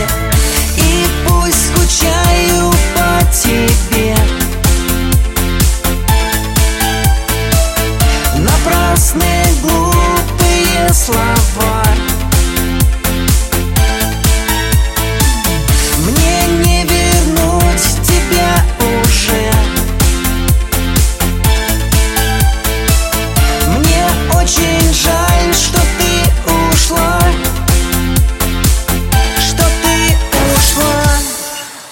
рингтон
припев